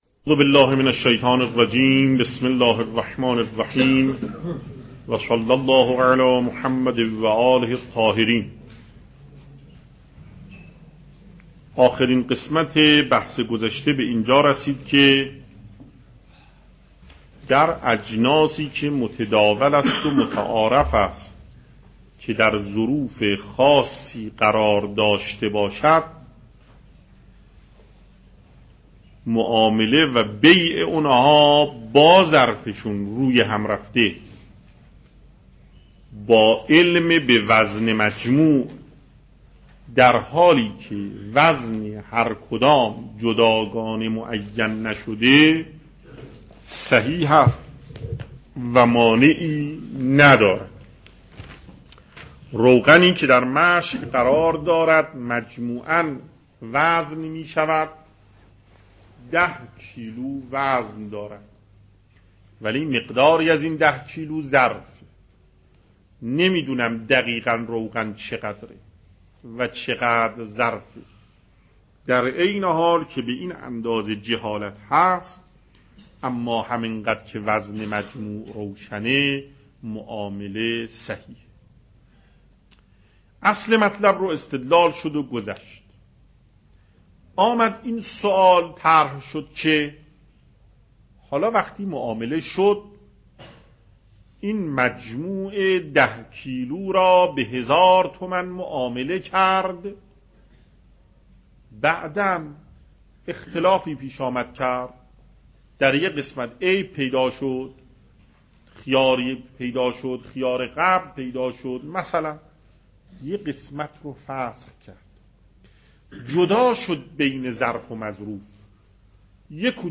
درس فقه آیت الله محقق داماد , درس مکاسب